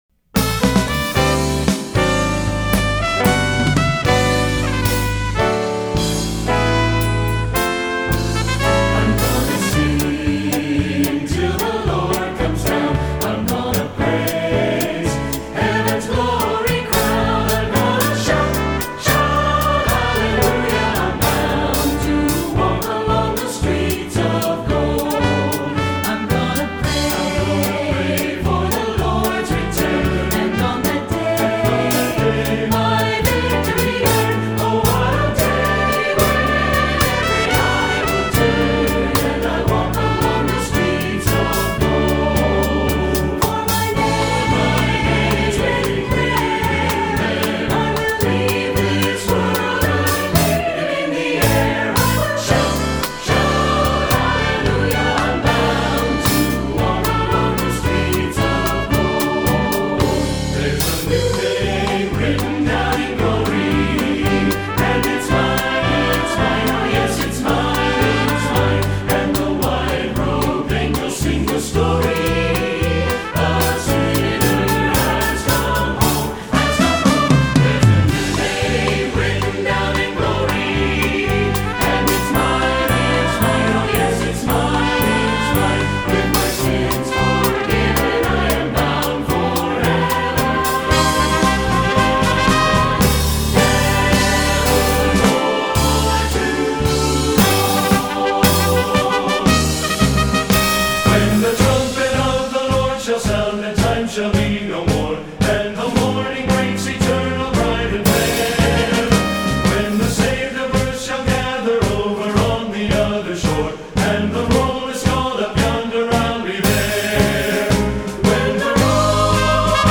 Voicing: TTBB and Piano